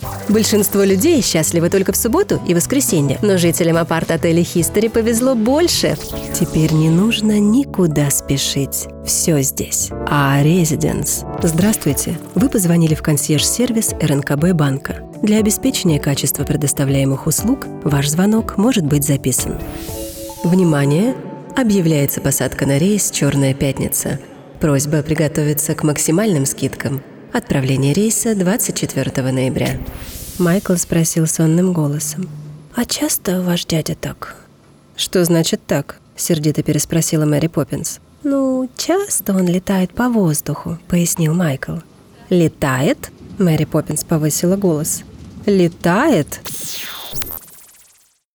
Жен, Рекламный ролик/Средний
Приятный и нежный голос с хорошей дикцией.